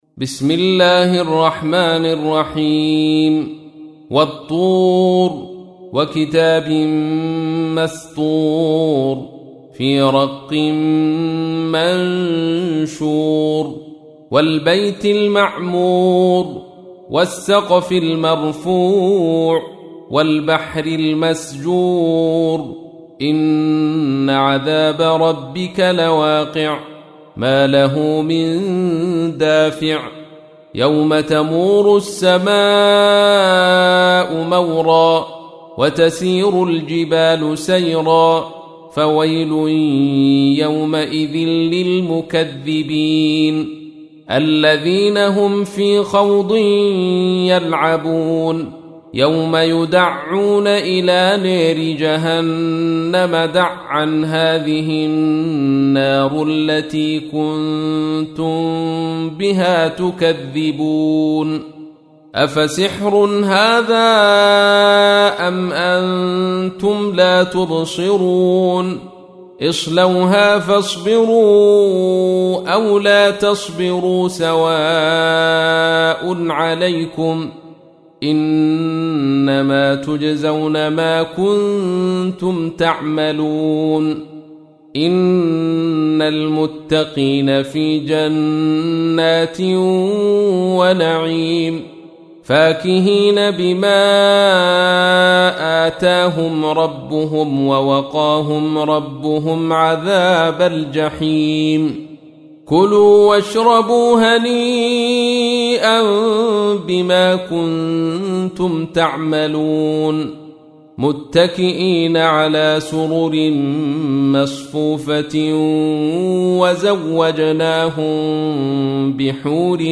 تحميل : 52. سورة الطور / القارئ عبد الرشيد صوفي / القرآن الكريم / موقع يا حسين